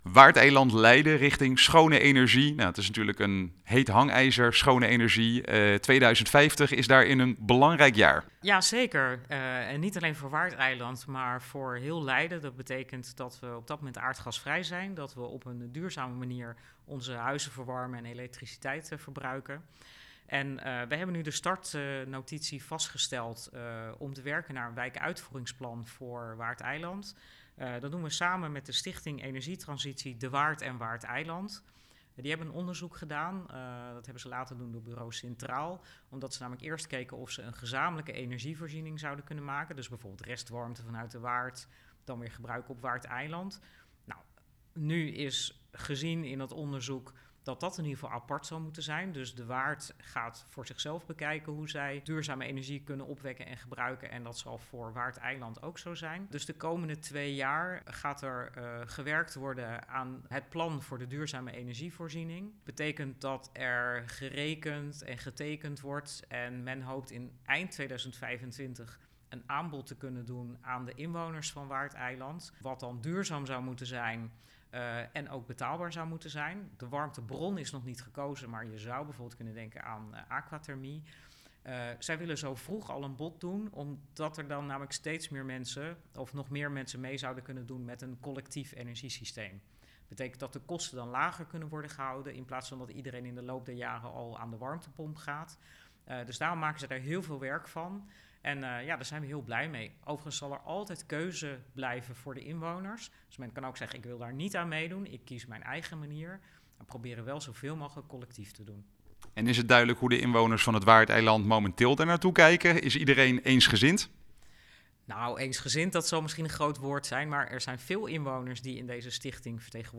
in gesprek met wethouder Yvonne van Delft.
Interview Leiden Politiek
Yvonne-over-Waardeiland.wav